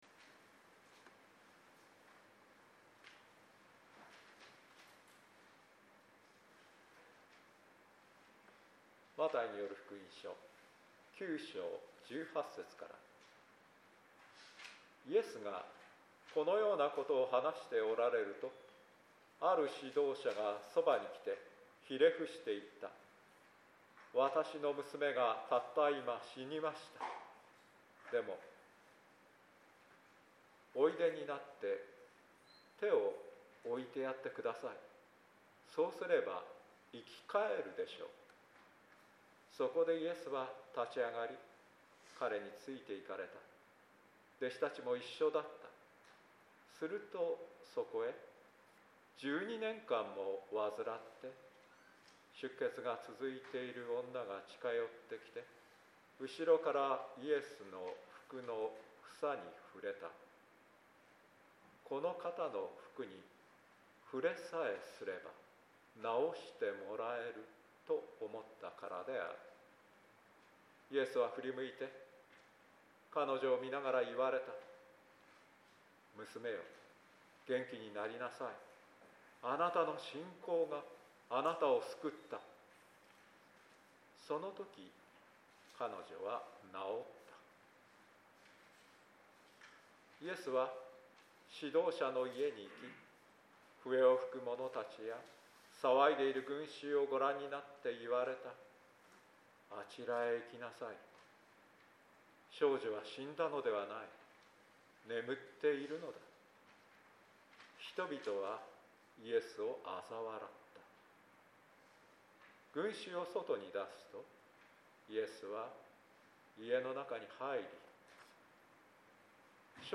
説教音声